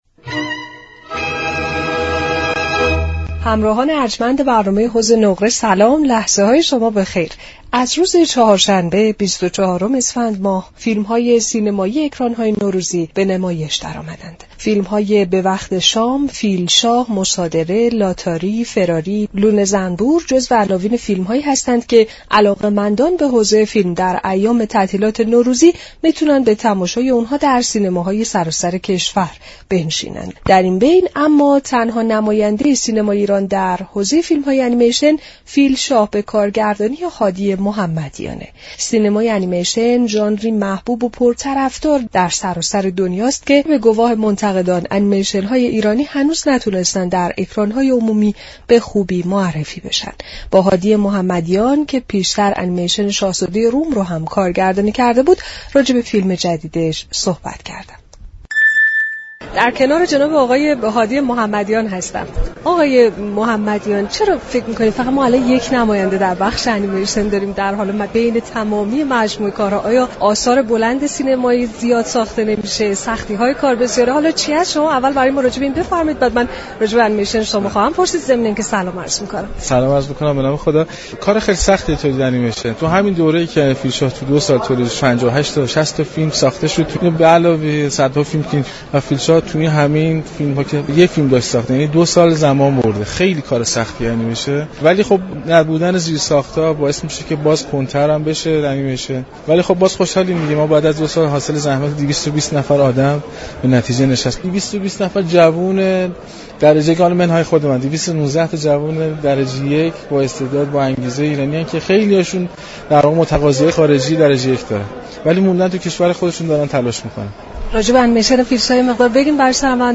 در گفت و گو با برنامه «حوض نقره»